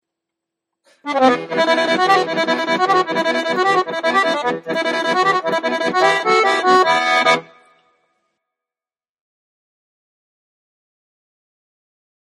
Und diesmal auch nichts Langsames, denn ich kann auch Gas geben, wenn es sein muss: Ist zwar sehr kurz, aber keine Bange, den Rest kann ich auch spielen. Name des Stücks?